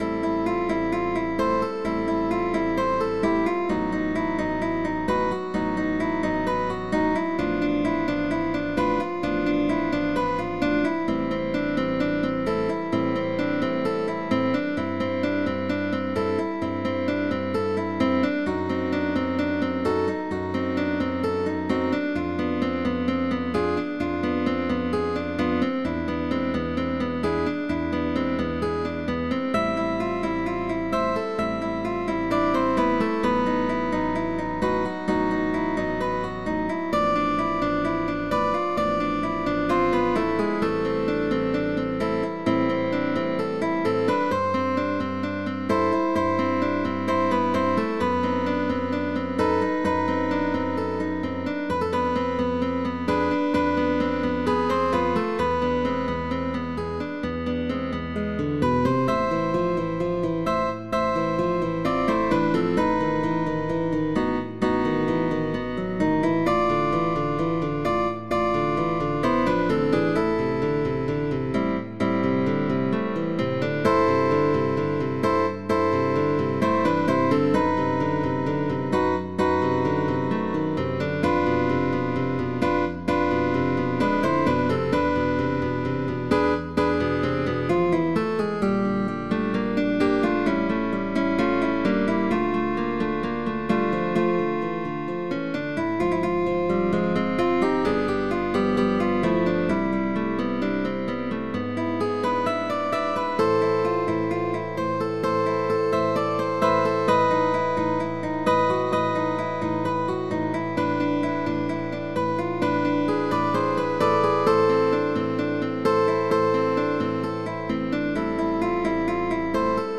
CUARTETO de GUITARRAS